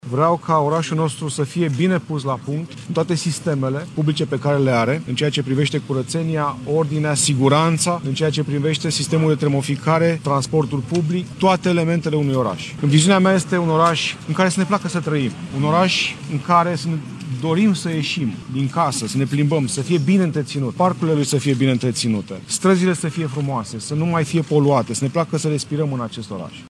Ciprian Ciucu a enumerat câteva dintre prioritățile sale dacă va ajunge primar general al Bucureștiului.